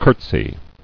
[curt·sy]